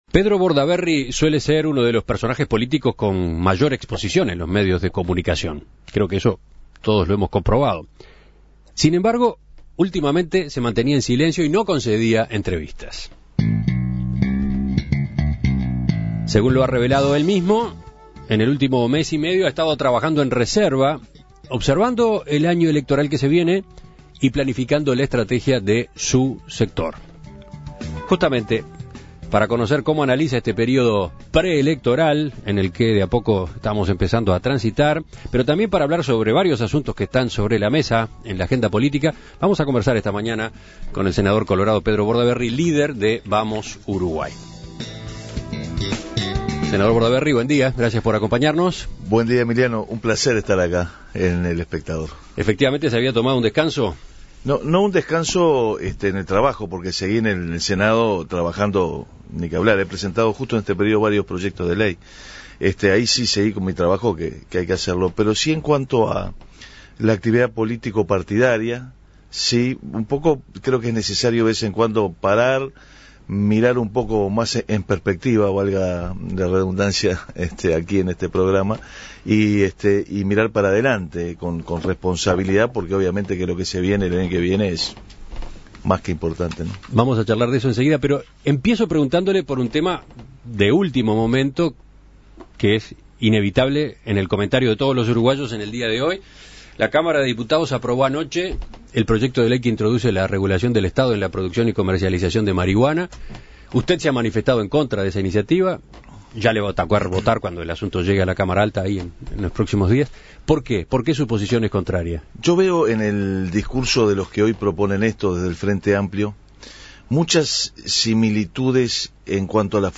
Escuche la entrevista a Pedro Bordaberry